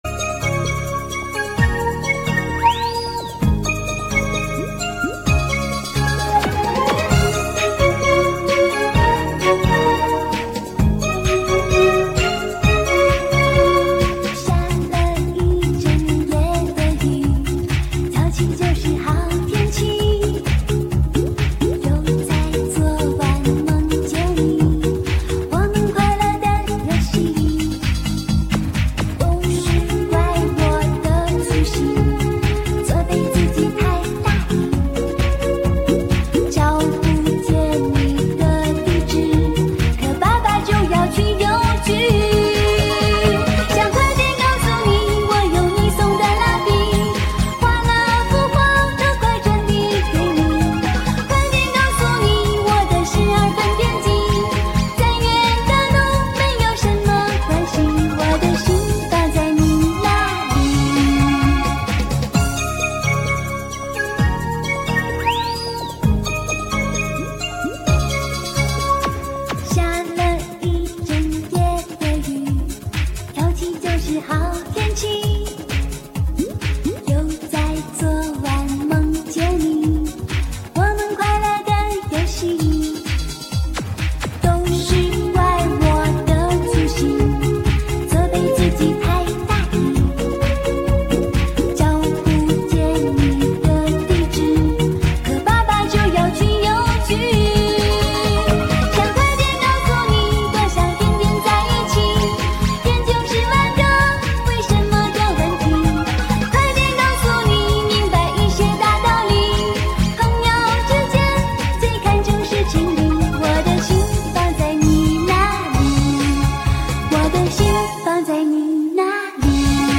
怀旧音乐
标签： 欢快愉悦